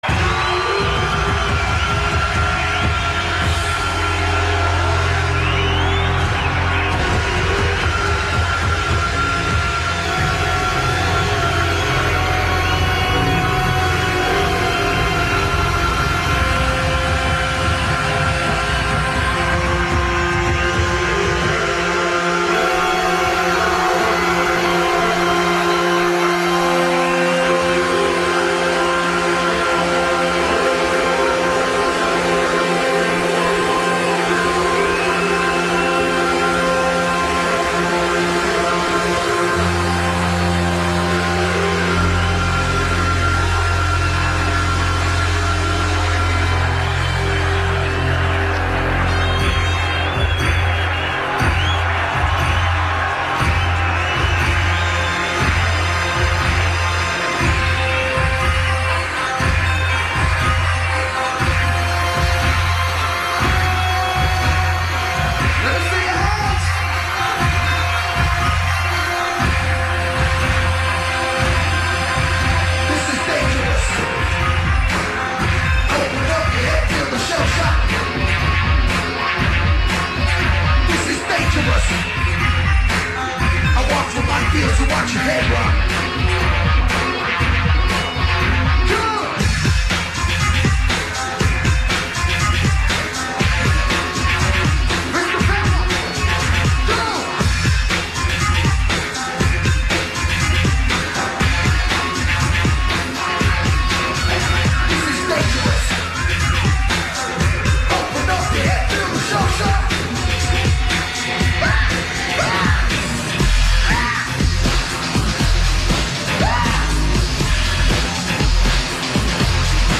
но гитары мне не понравились.